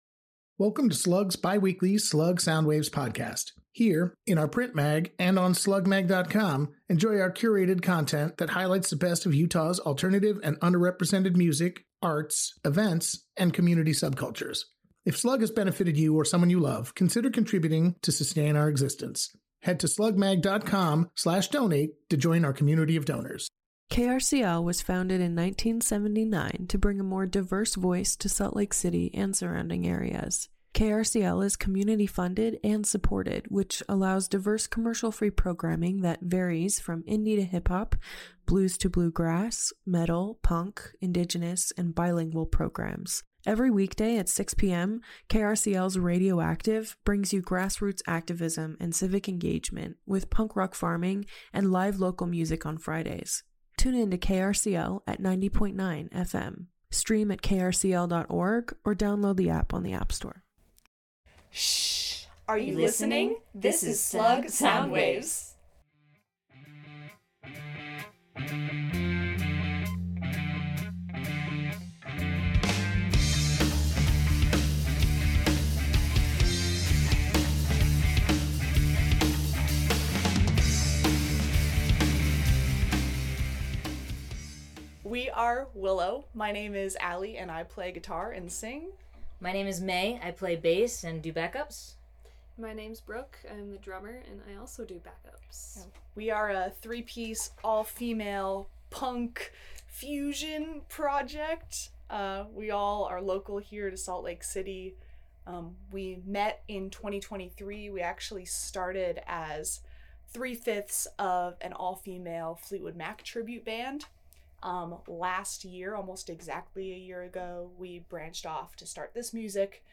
Willoh is a three-piece, all-female, fusion project. Originally influenced by the blues, what came once they started making music was a bit more punk.